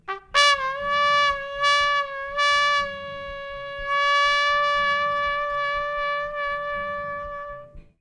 trumpet.wav